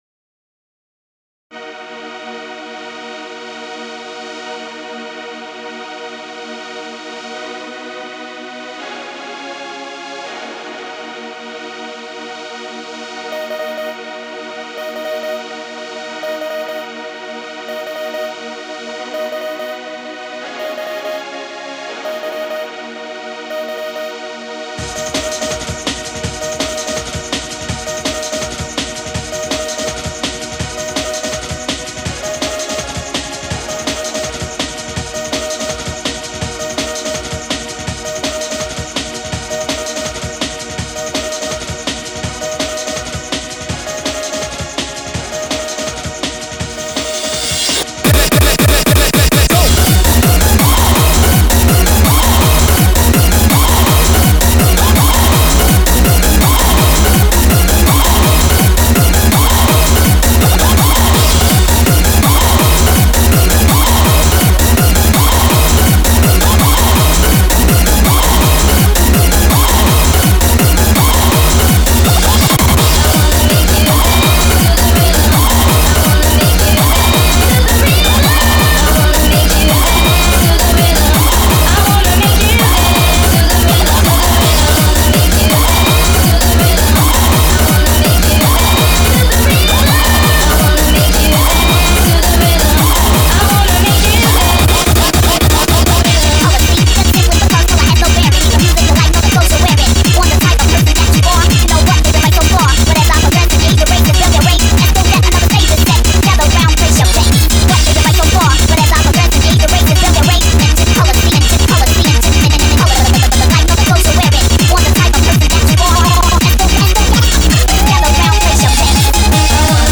but it's so loud and energetic.